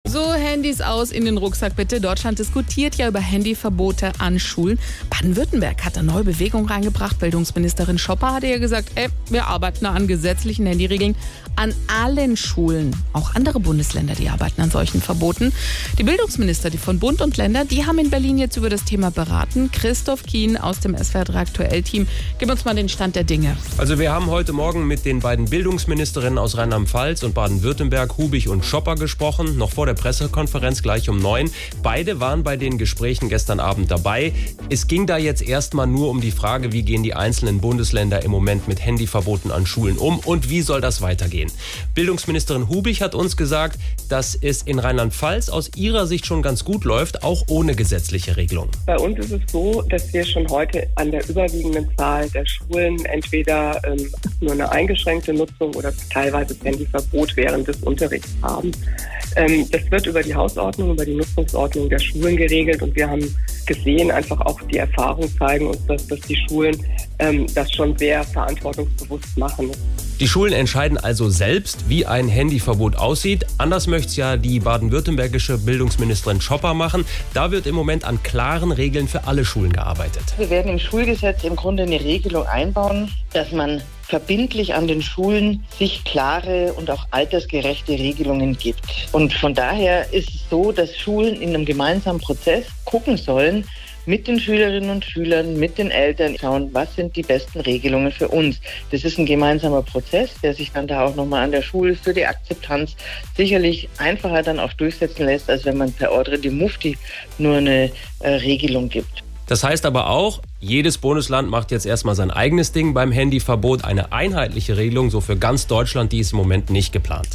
Wir haben mit den Bildungsministerinnen von Baden-Württemberg, Theresa Schopper (Grüne), und Rheinland-Pfalz, Stefanie Hubig (SPD) gesprochen, wie die Situation rund ums Handy an Schulen momentan geregelt ist.